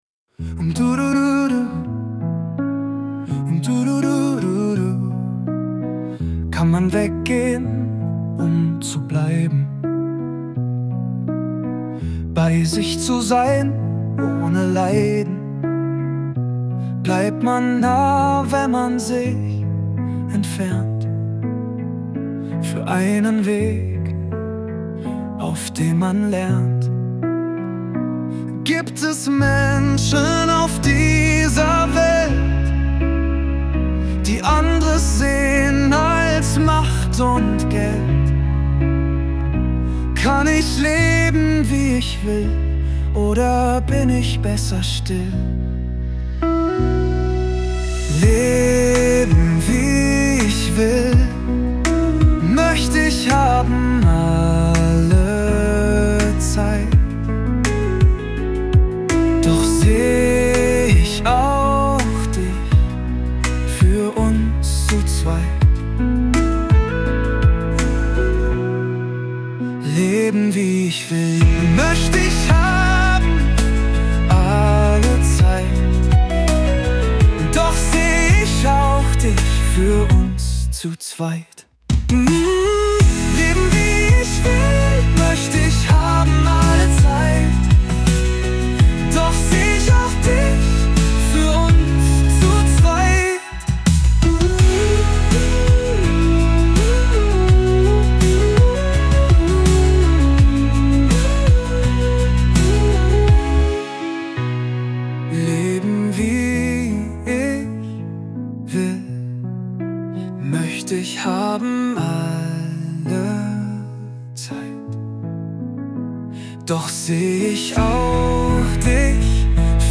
Genre:                  Rock/Pop - Deutsch